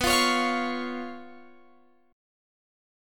Bsus2b5 chord